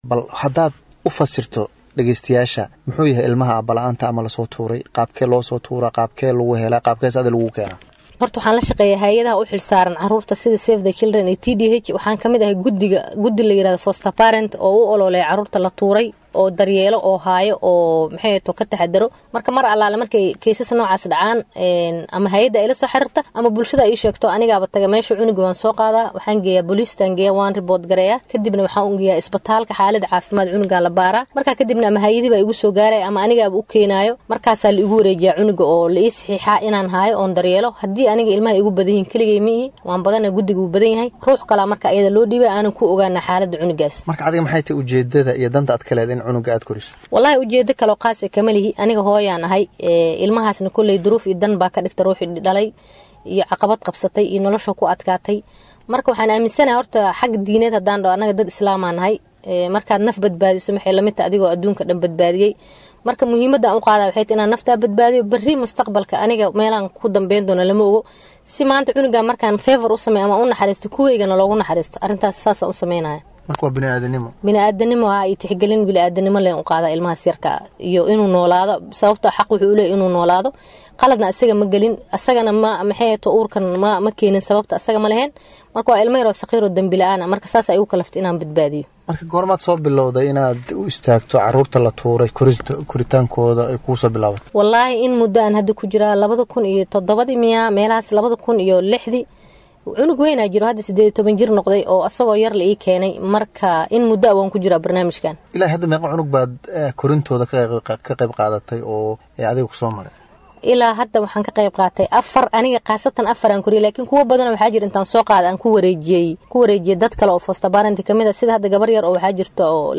Wareysi-Hooyada-carruurta-korisa.mp3